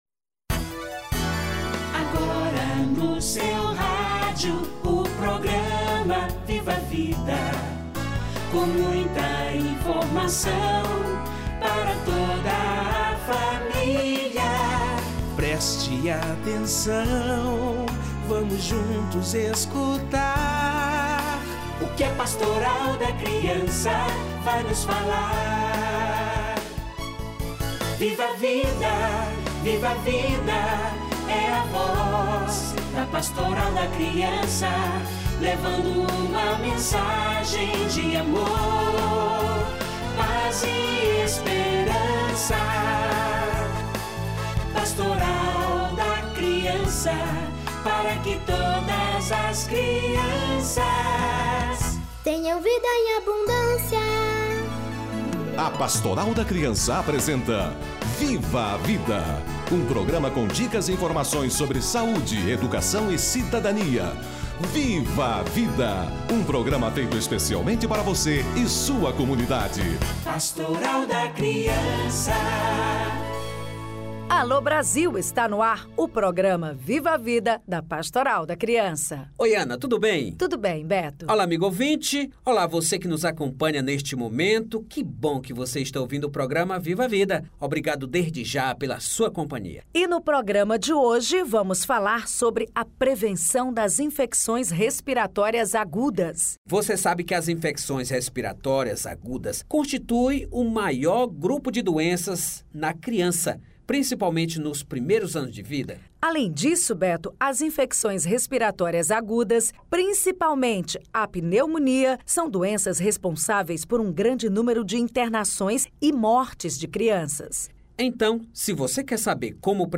Infecções respiratórias agudas - Entrevista